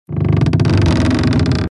Звуки растущего дерева
На этой странице представлены редкие звуки растущего дерева — от едва уловимого шепота молодых побегов до глубоких вибраций старого ствола.